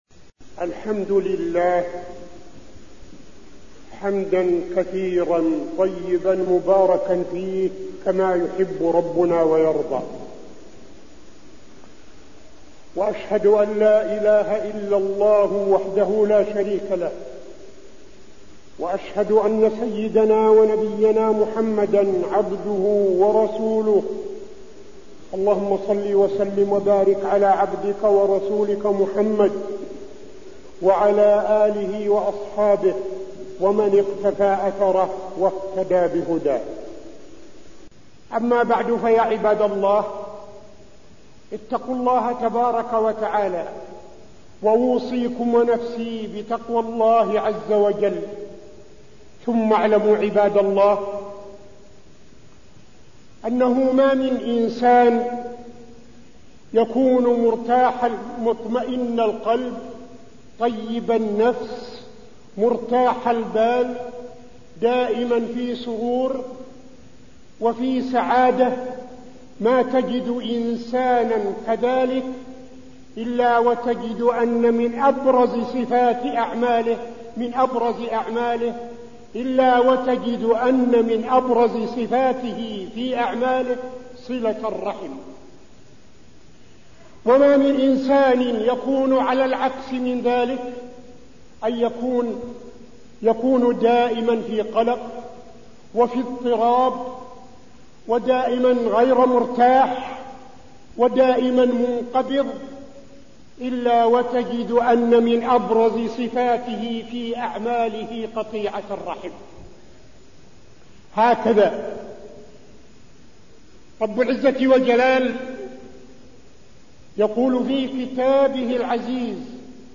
تاريخ النشر ٢٠ محرم ١٤٠٦ هـ المكان: المسجد النبوي الشيخ: فضيلة الشيخ عبدالعزيز بن صالح فضيلة الشيخ عبدالعزيز بن صالح صلة الرحم